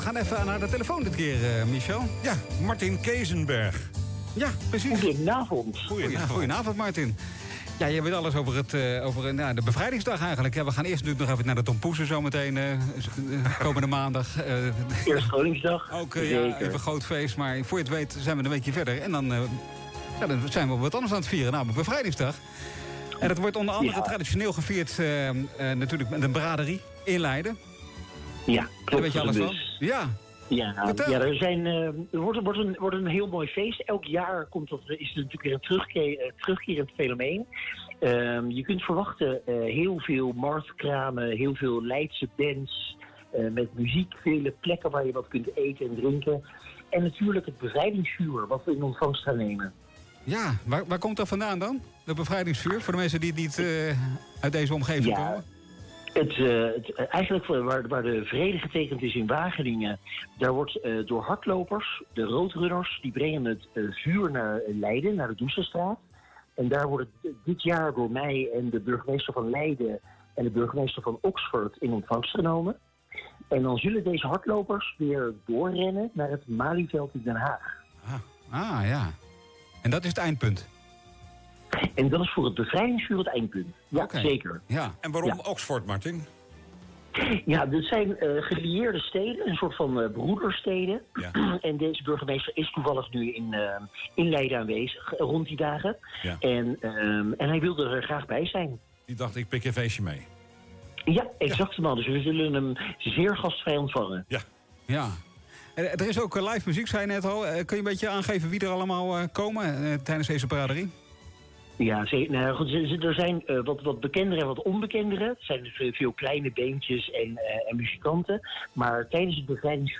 Afgelopen vrijdag was in het programma De VrijMiBo van streekomroep Centraal+ aandacht voor de bevrijdingsbraderie in Leiden.